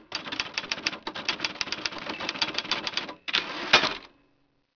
typing0c.wav